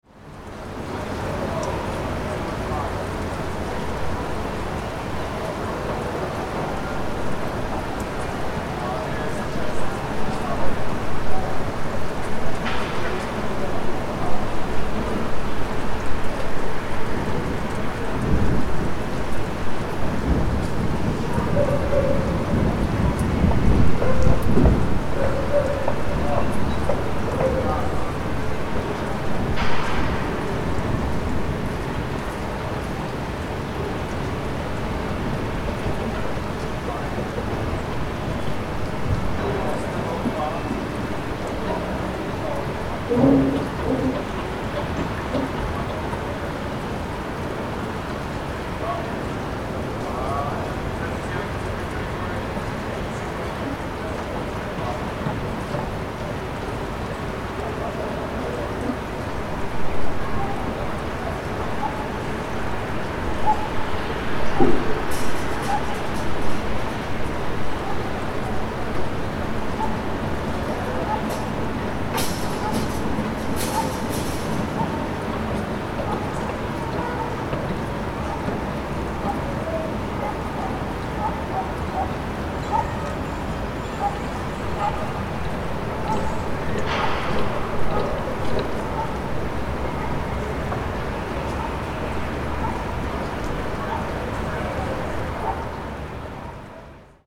Construction Site In Rainy Weather: Authentic Ambience
Experience the realistic ambience of an indoor construction site in an urban area. You can hear thunder, rain, and workers hammering in the background. Perfect for videos, games, or documentaries needing authentic industrial rain atmosphere.
Construction-site-in-rainy-weather-sound-effect.mp3